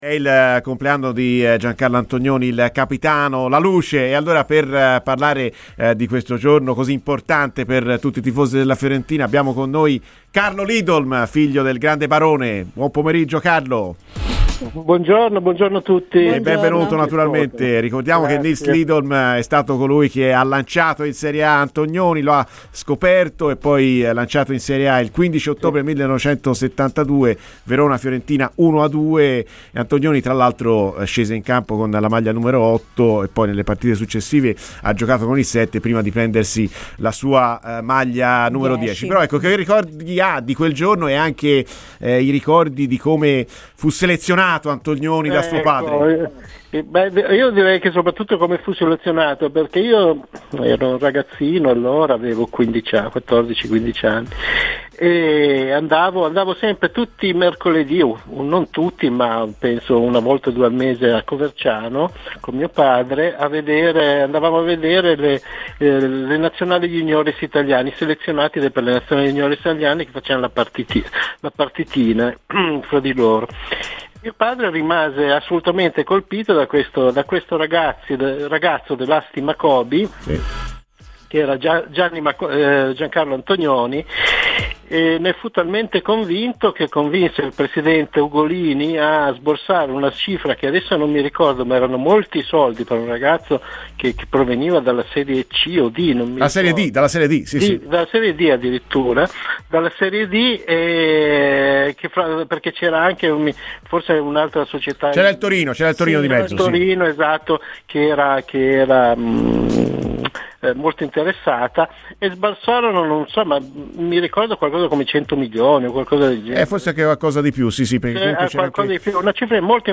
ha parlato a "Viola amore mio" in onda su Radio FirenzeViola